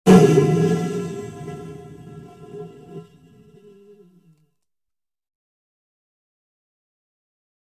Air Burst Sweetener Through Pipe, Low Flange Hiss That Starts Heavy And Dies Out